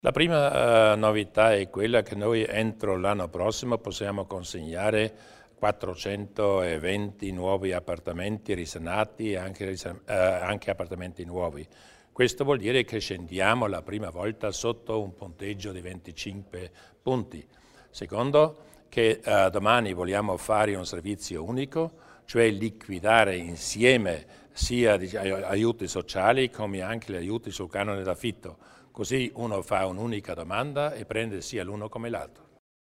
Il Presidente Durnwalder spiega le novità che interessano l'edilizia abitativa agevolata